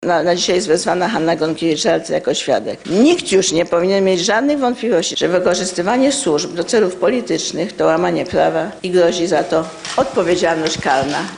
Prezydent Warszawy podczas swojej konferencji prasowej przypomniała, że decyzja w sprawie kamienicy przy Noakowskiego została wydana w 2003 roku, kiedy w Śródmieściu rządzili przedstawiciele Prawa i Sprawiedliwości.